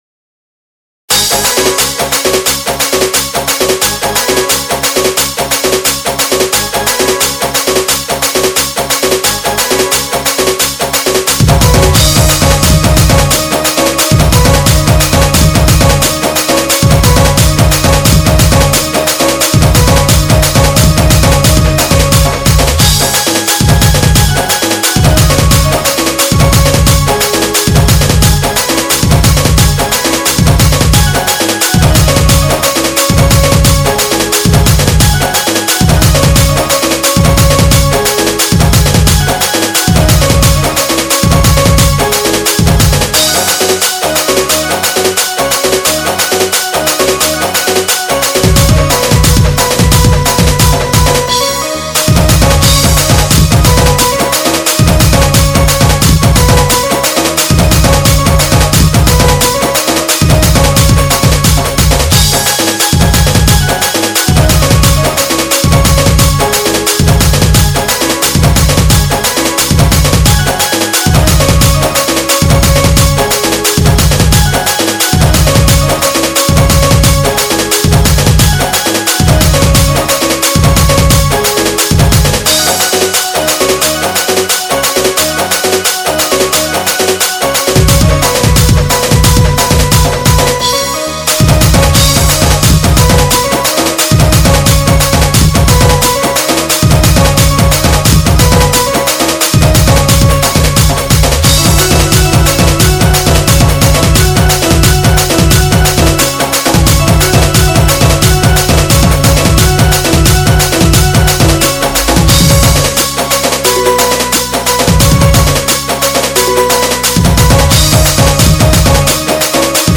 SAMBALPURI INSTRUMENT DJ REMIX